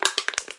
罐子 " cokecan52
描述：在木地板上记录各种（空）罐头焦炭的各种操作。录制了第五代iPod touch。用Audacity编辑
标签： 可乐 壁虱 滚动 锡 - 可以 可以 地点 挤压 焦炭可以 我TAL 移动 焦炭 命中 紧缩
声道立体声